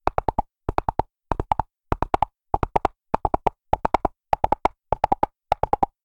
sounds_gallop_01.ogg